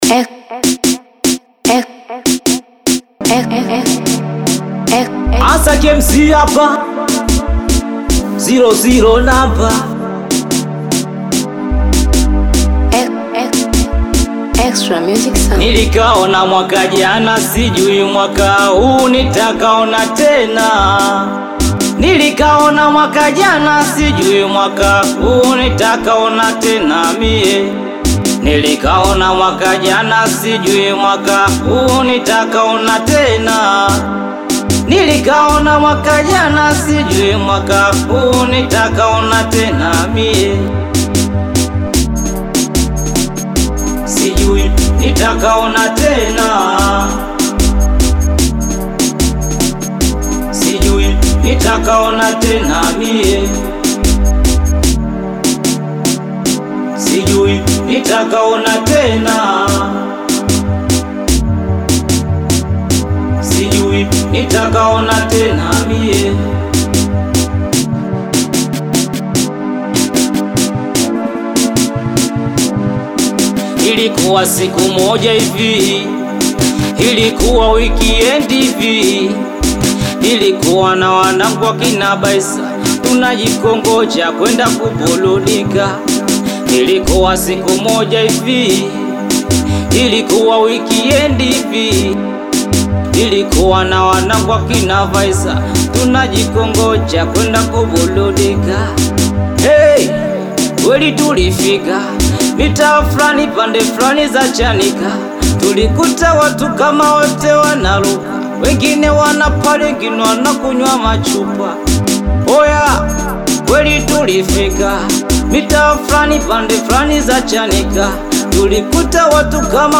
SINGELI MUSIC